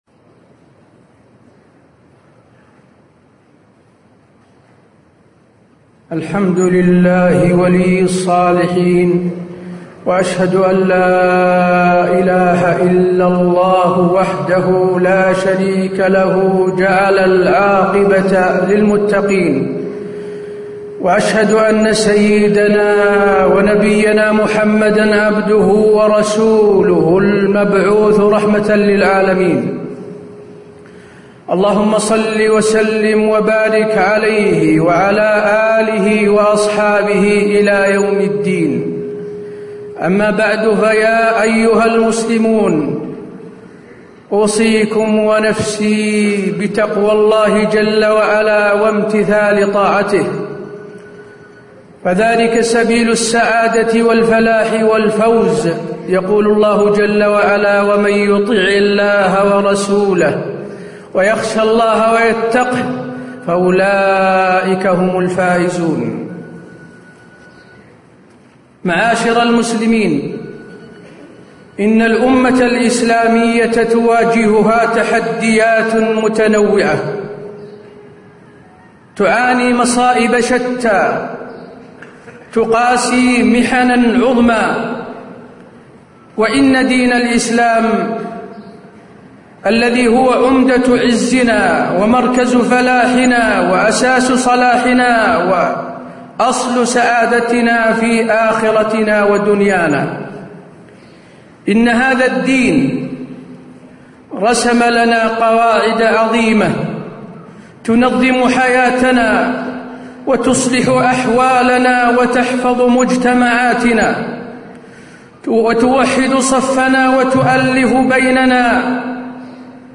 تاريخ النشر ٢٩ رجب ١٤٣٧ هـ المكان: المسجد النبوي الشيخ: فضيلة الشيخ د. حسين بن عبدالعزيز آل الشيخ فضيلة الشيخ د. حسين بن عبدالعزيز آل الشيخ سورة العصر توجيهات وعبر